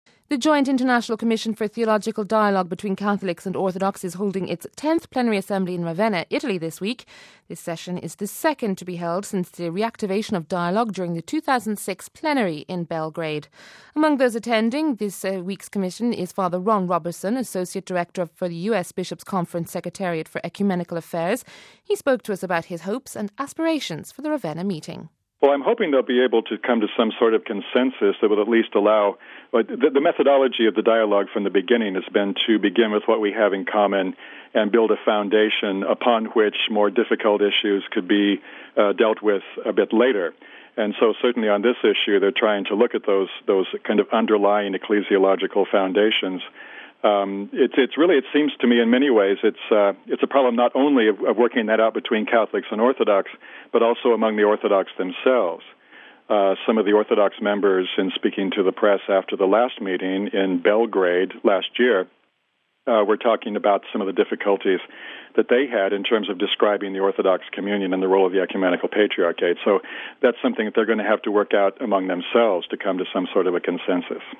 He spoke to us about his hopes and aspirations for the Ravenna meeting…